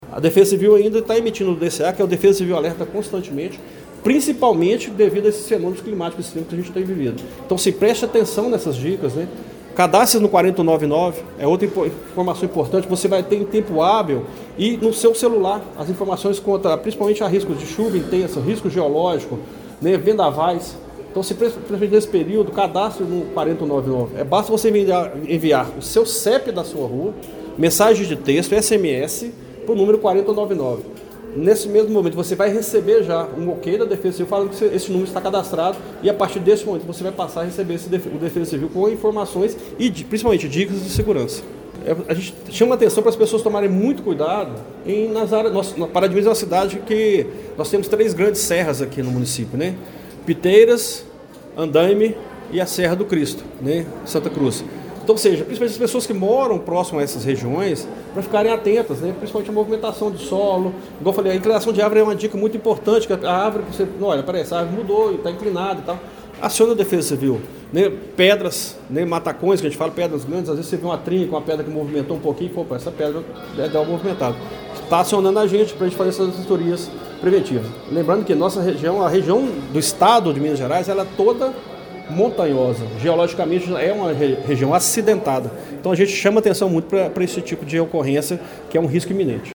O coordenador de Proteção e Defesa Civil, Edson Cecílio da Silva, apresentou uma análise detalhada do cenário climático esperado para os próximos dias em Pará de Minas, durante coletiva de imprensa realizada na tarde de ontem (24).